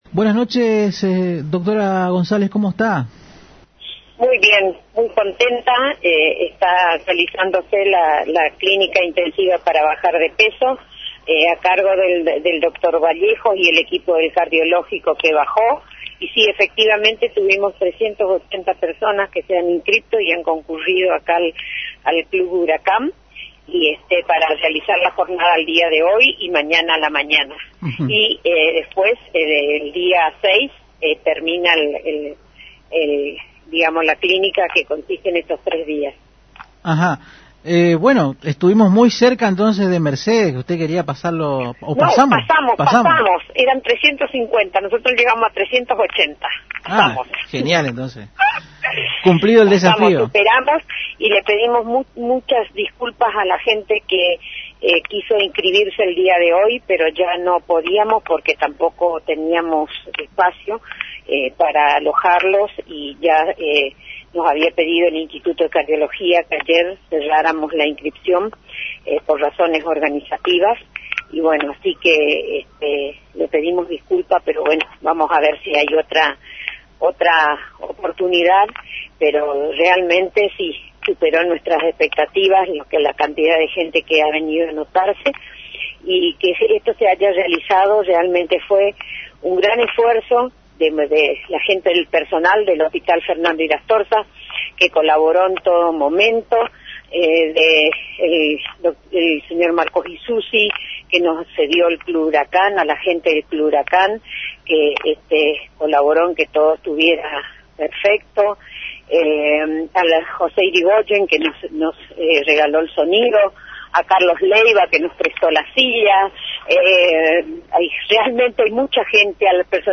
en diálogo con Agenda 970 a través de la AM 970 Radio Guarani destacó la enorme convocatoria lograda en la Clínica intensiva para bajar de peso